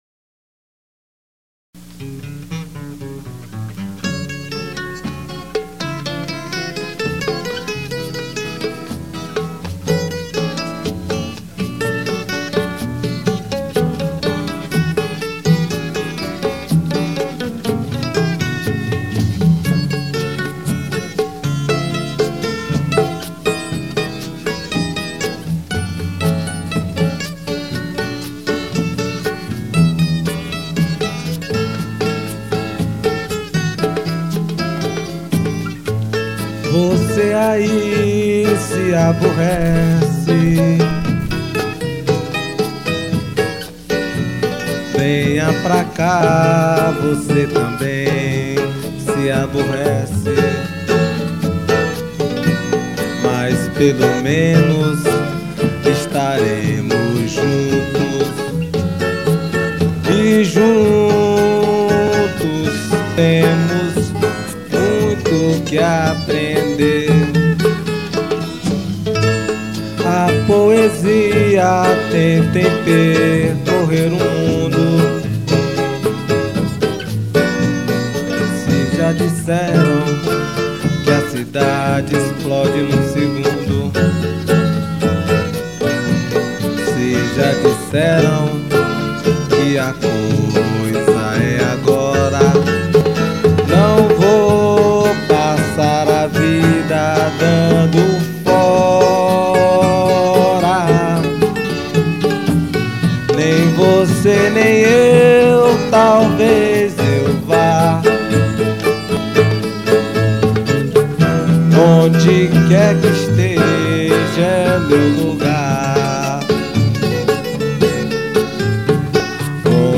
979   04:35:00   Faixa:     Rock Nacional